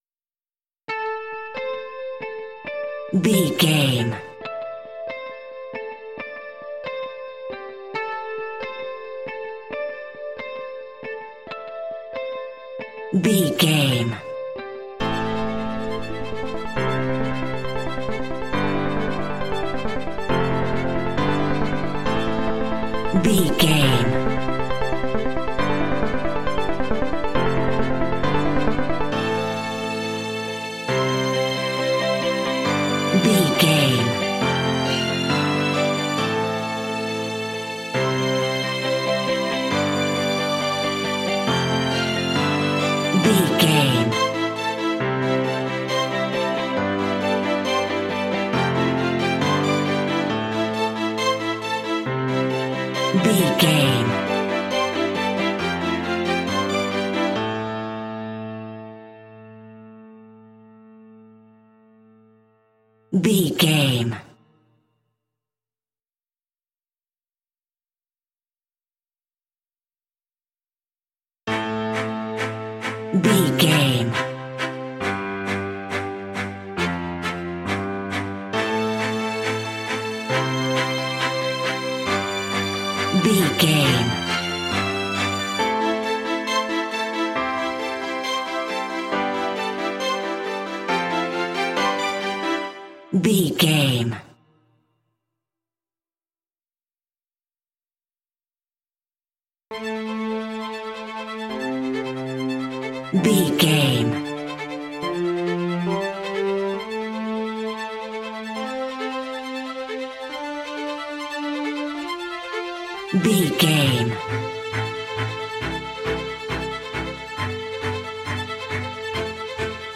Epic / Action
Fast paced
Aeolian/Minor
driving
energetic
piano
electric guitar
synthesiser
synth pop
alternative rock